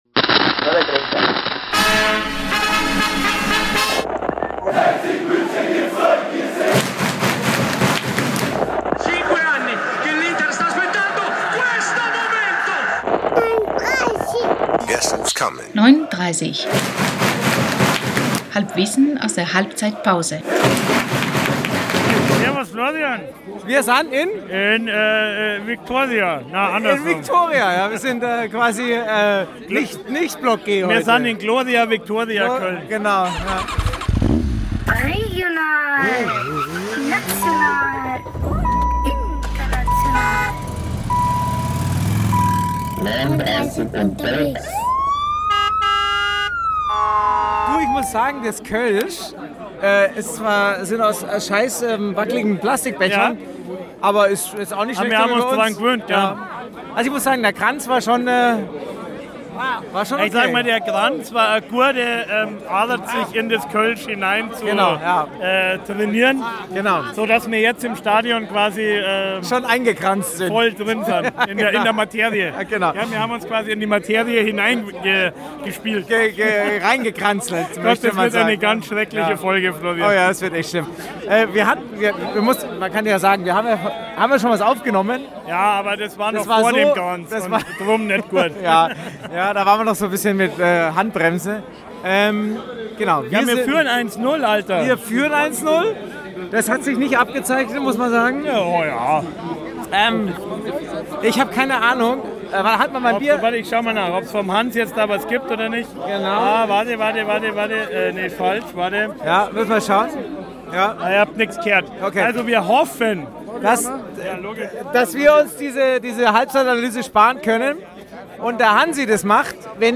Der Podcast aus der Westkurve im Grünwalderstadion bei den Spielen des TSV 1860.